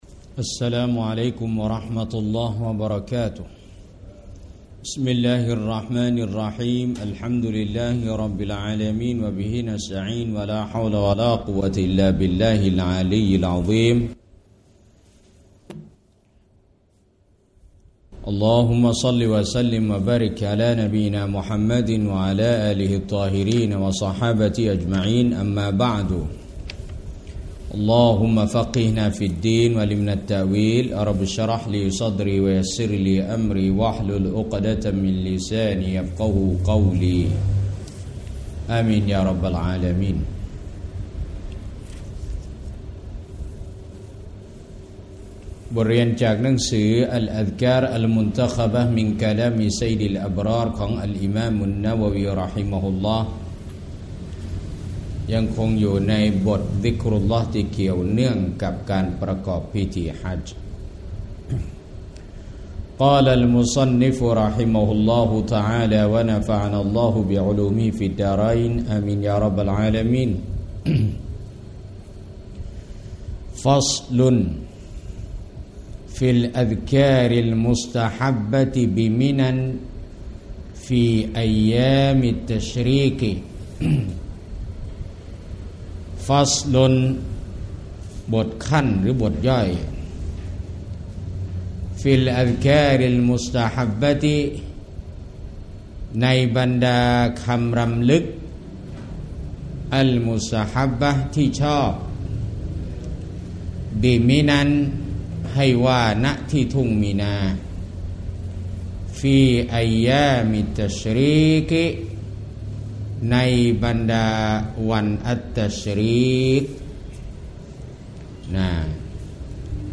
สถานที่ : โรงเรียนญะมาลุ้ลอัซฮัร, อิสลามรักสงบ ซ.พัฒนาการ 30